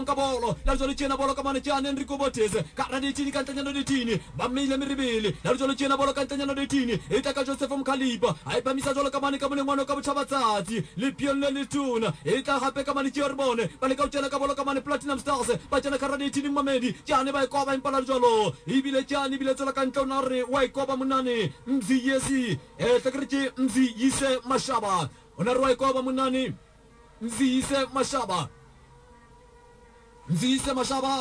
Soccer match on radio